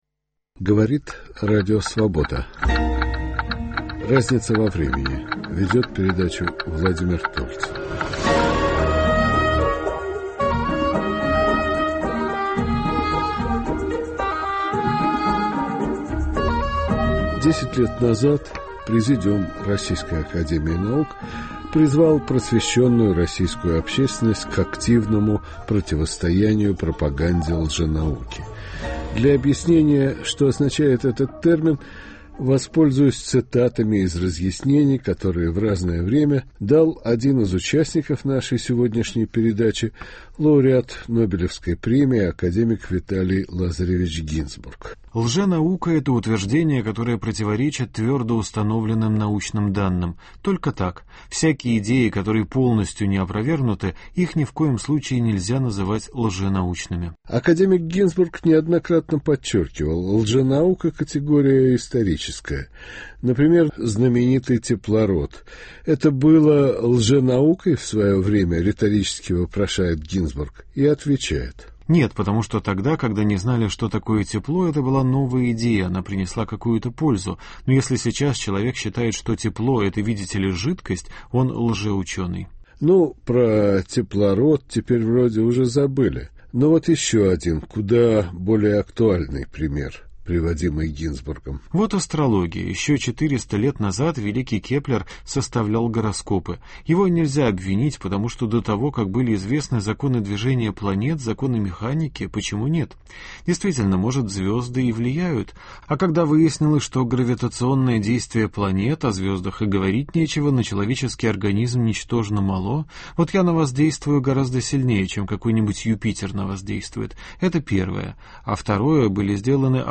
беседует с академиками Виталием Гинзбургом и Исааком Халатниковым